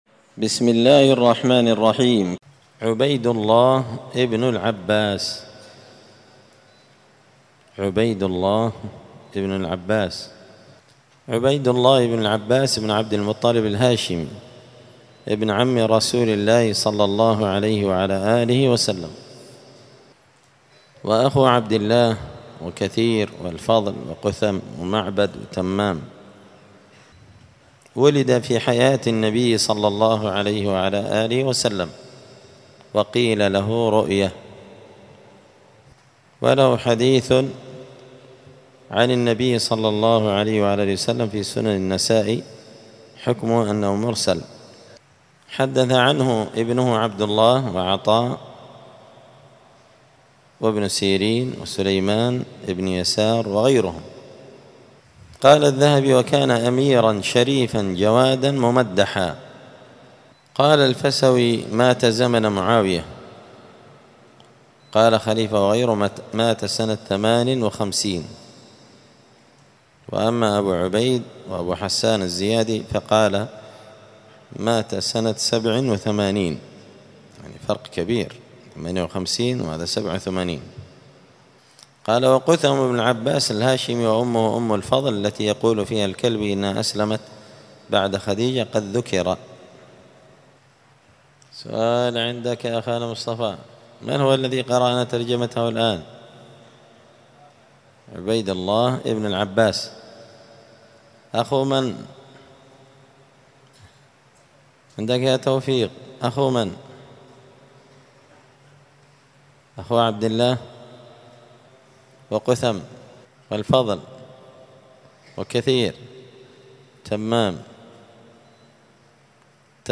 قراءة تراجم من تهذيب سير أعلام النبلاء
دار الحديث السلفية بمسجد الفرقان قشن المهرة اليمن